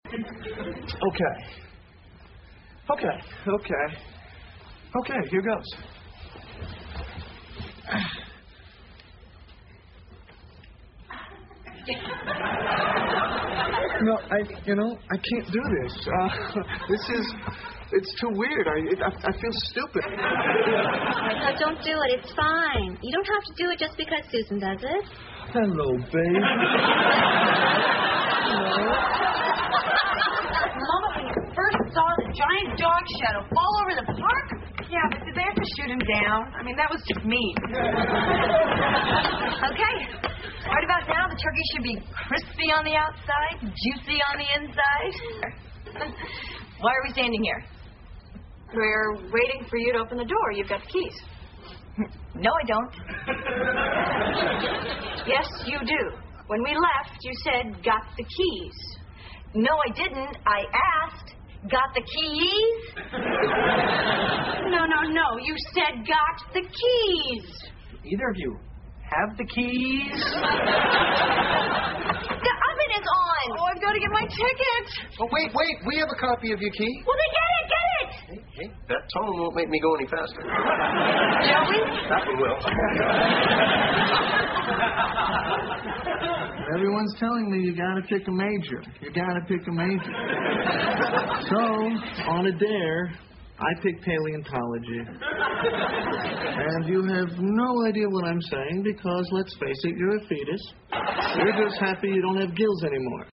在线英语听力室老友记精校版第1季 第108期:气球飞走了(9)的听力文件下载, 《老友记精校版》是美国乃至全世界最受欢迎的情景喜剧，一共拍摄了10季，以其幽默的对白和与现实生活的贴近吸引了无数的观众，精校版栏目搭配高音质音频与同步双语字幕，是练习提升英语听力水平，积累英语知识的好帮手。